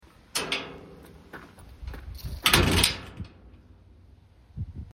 portail Maispnnay MP3 2024.mp3